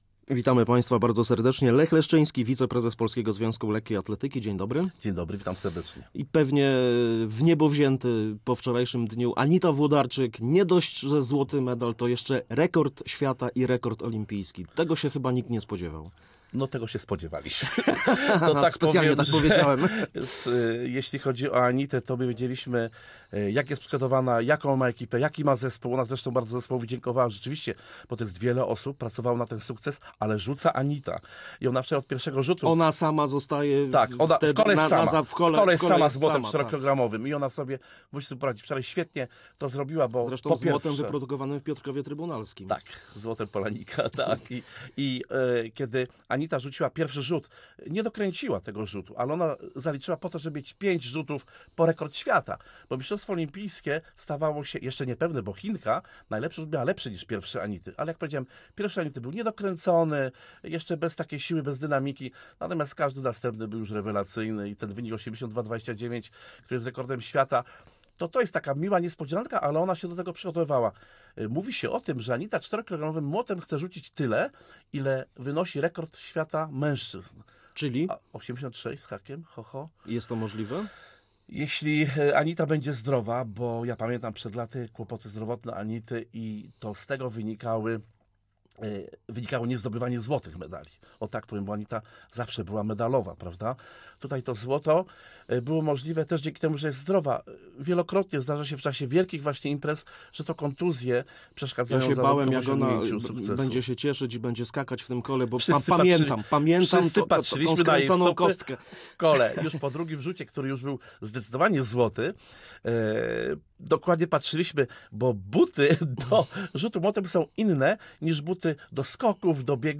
Gościem Radia Łódź